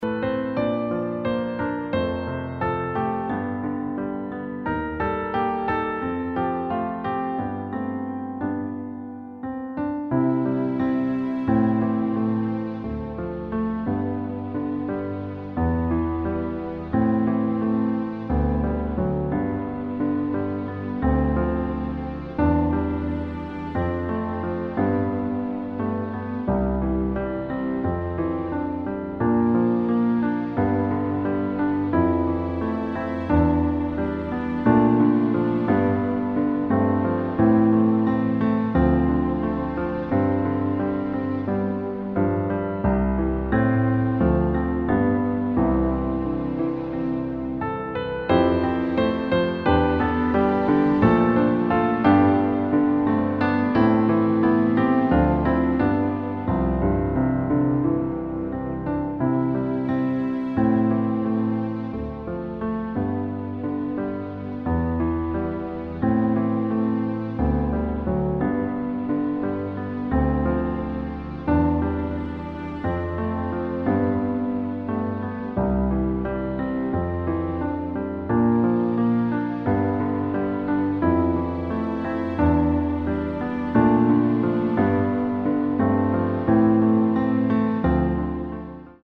Klavierversion
• Tonart: Bb Dur, C Dur, D Dur (weitere auf Anfrage)
• Art: Klavier Streicher Version
• Das Instrumental beinhaltet NICHT die Leadstimme
Klavier / Streicher